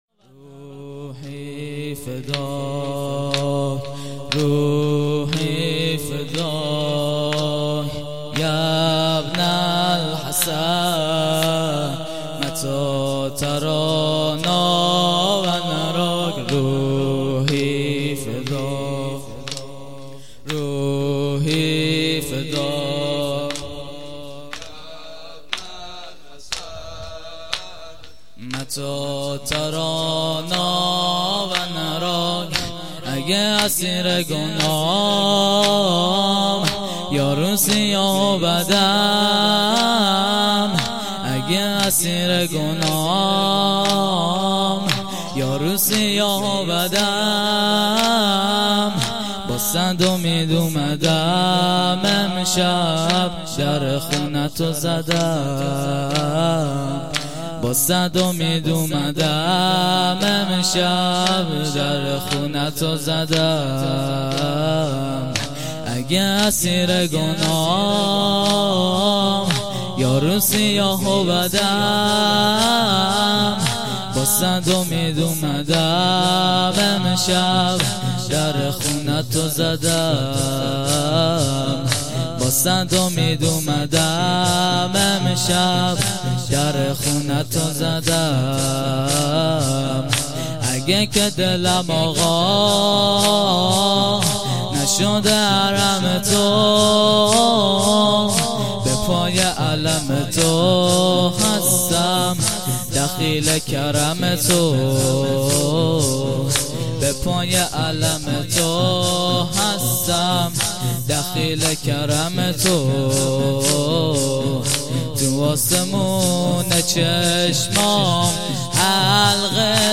روضه هفتگی ۹۶۱۰۱۴